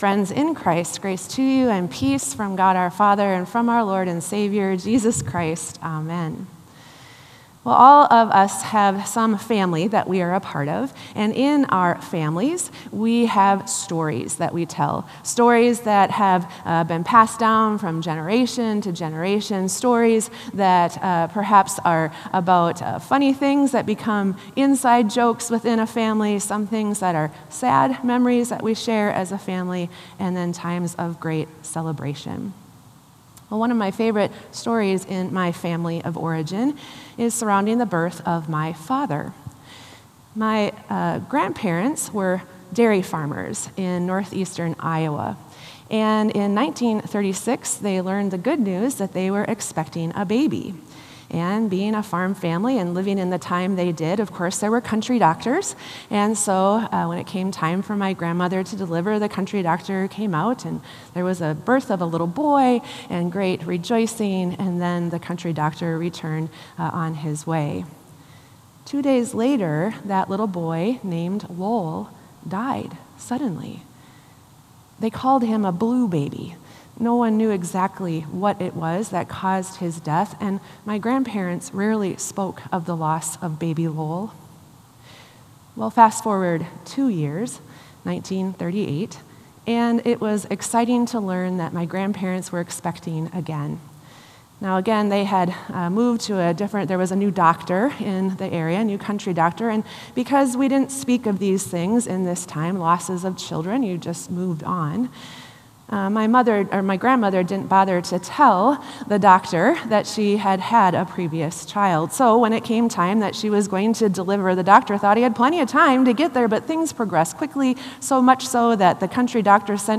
Sermon “What a Surprise!”